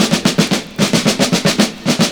112FILLS08.wav